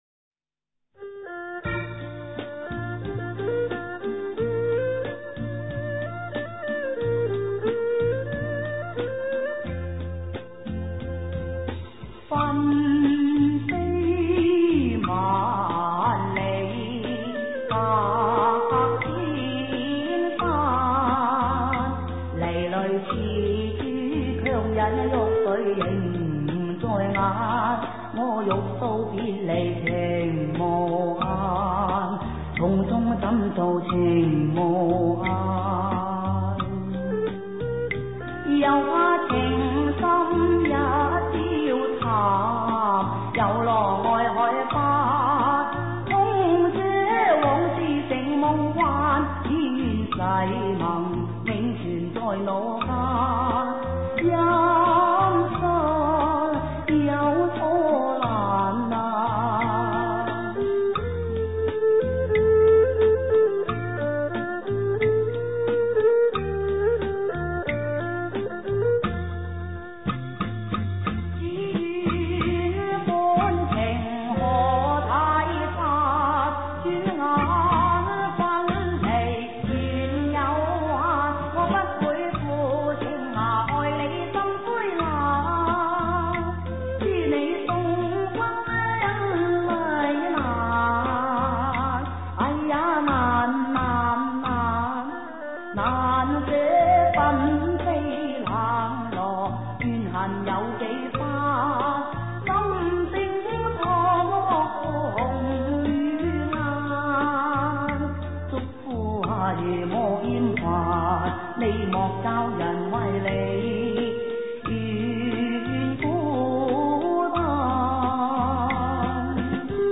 歌曲原來是對唱小曲，現在改為敘述式演唱，另有一番風味。